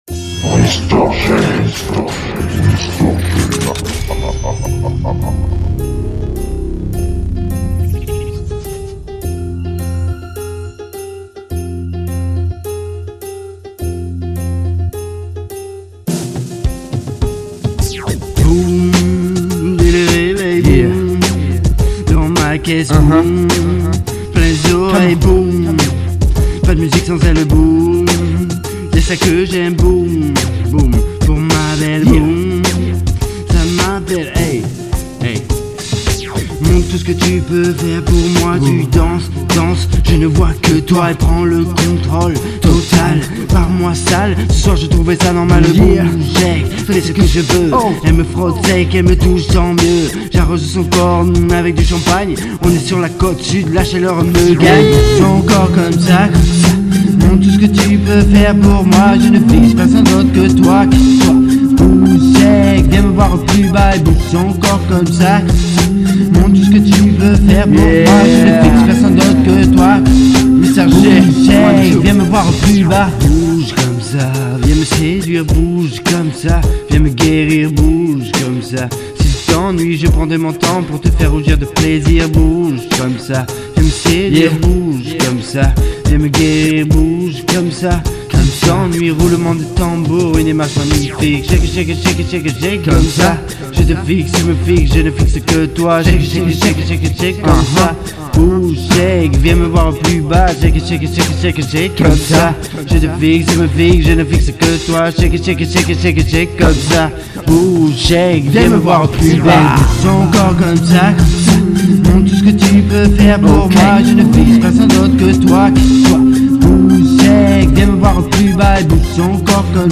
Trap music dirty south